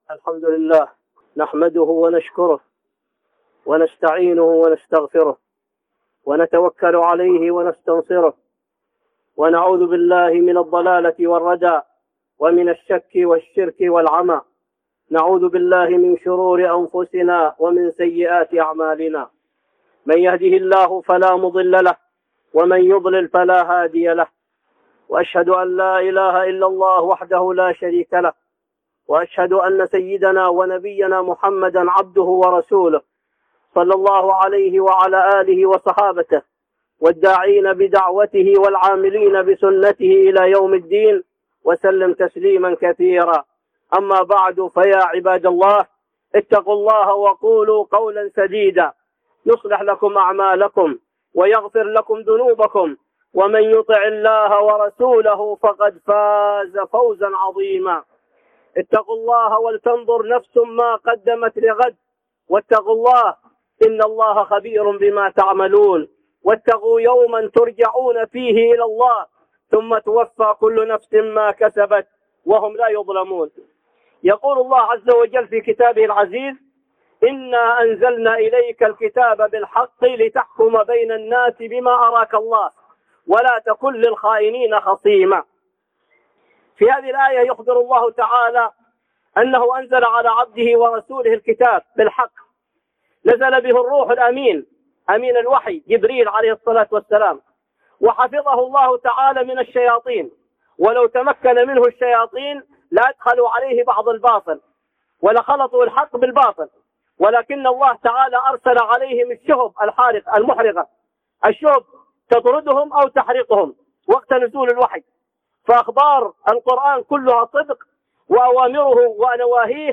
خطبة جمعة بعنوان